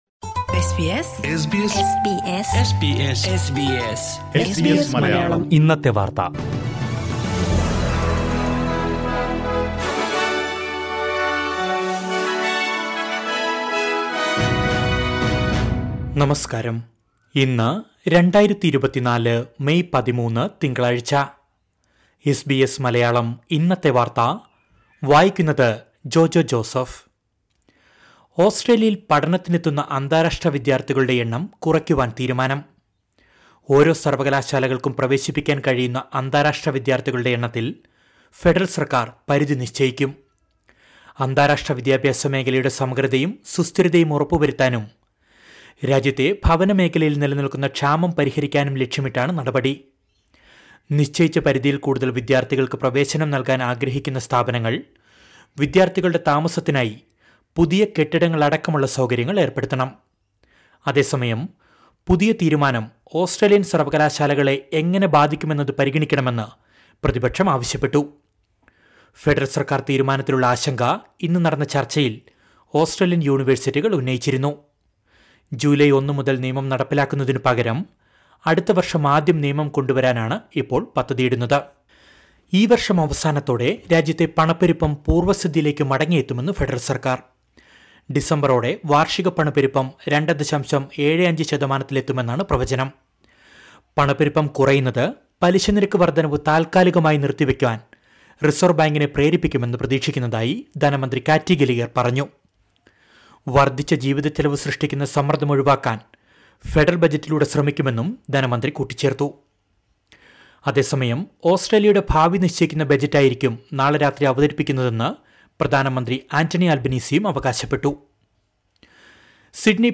2024 മെയ് 13ലെ ഓസ്‌ട്രേലിയയിലെ ഏറ്റവും പ്രധാന വാര്‍ത്തകള്‍ കേള്‍ക്കാം...